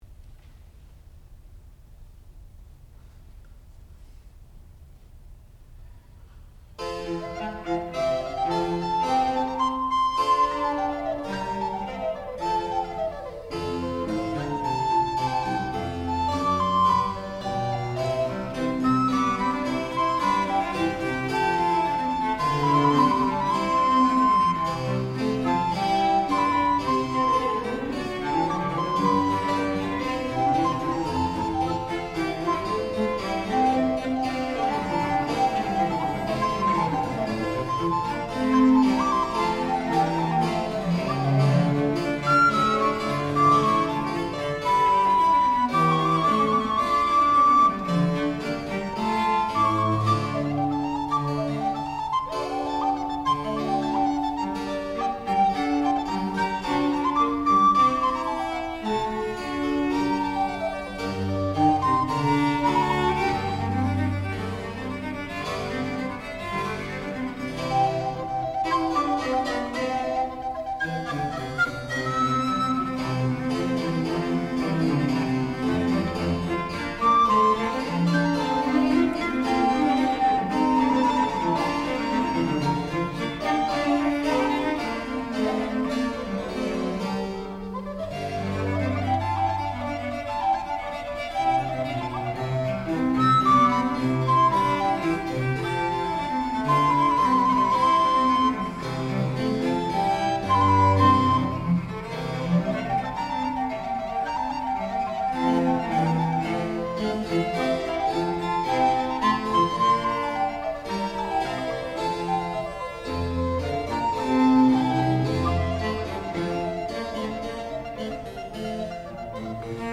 sound recording-musical
classical music
violoncello
harpsichord
viola da gamba
recorder